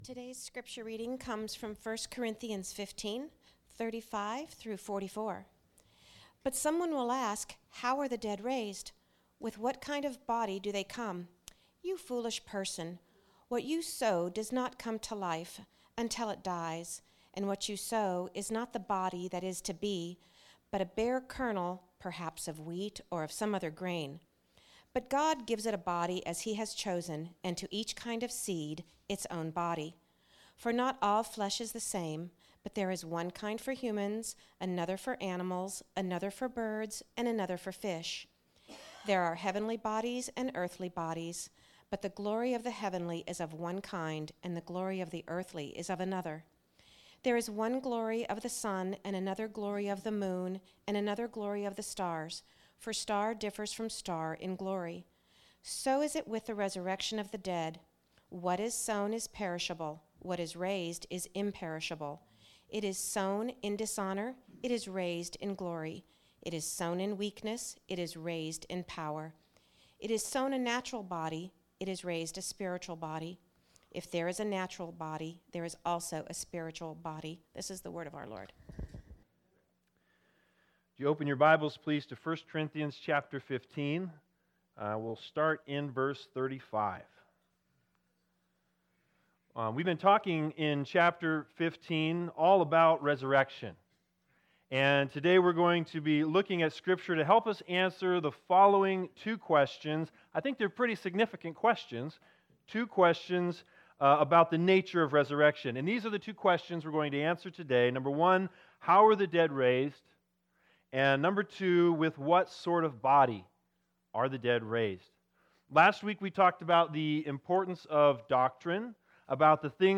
01/26/2020 The Body That is To Be Preacher